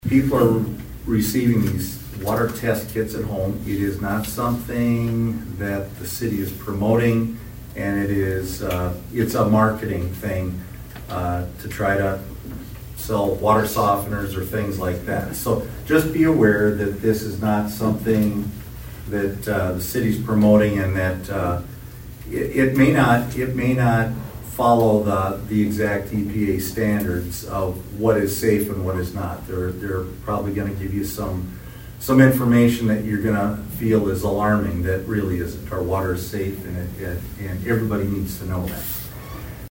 ABERDEEN, S.D.(HubCityRadio)- Aberdeen City Manager Robin Bobzien toward the end of the council meeting addressed a couple of water related topics during his City Manager Report.